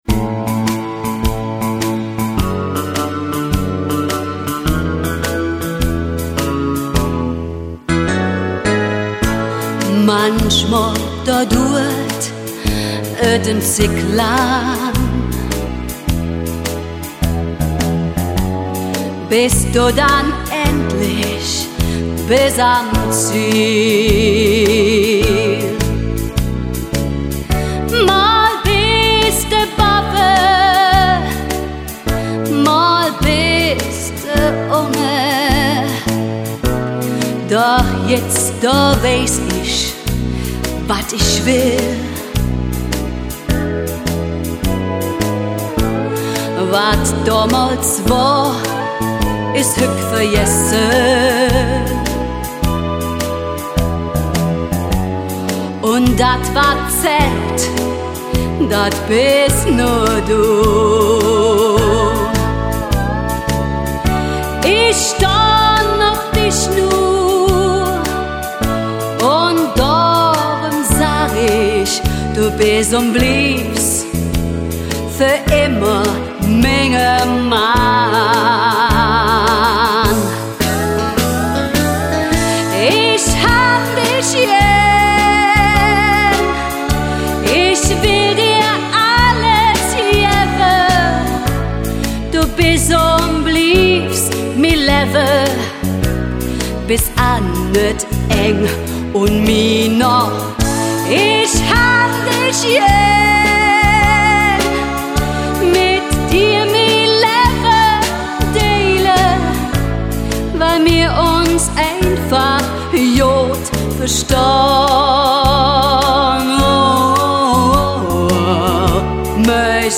erste kölsche Version